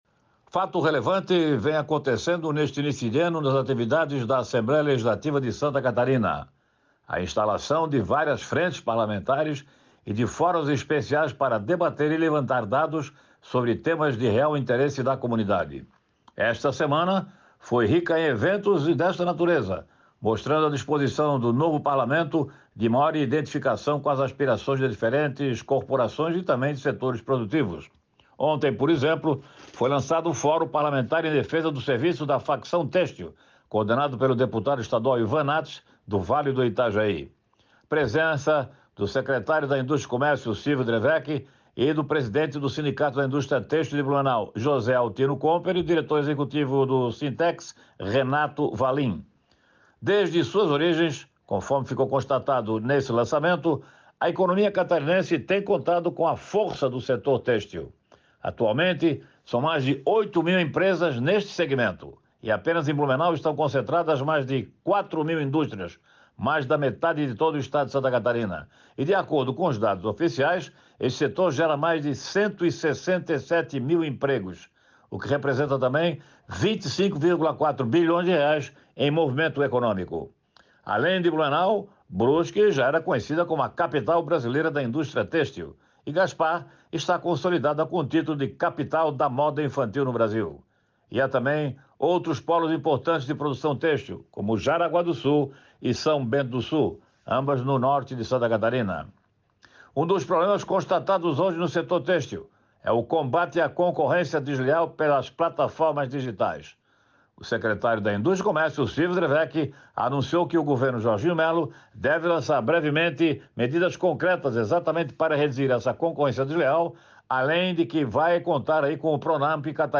Confira na íntegra o comentário: